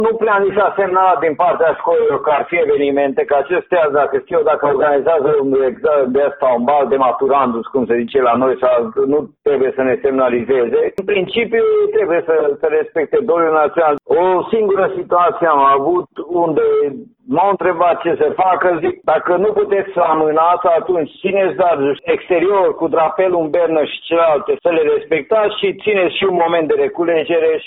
Inspectorul școlar general al județului Harghita, Petru Gârbea, a lăsat la latitudinea școlilor anularea sau nu a evenimentelor: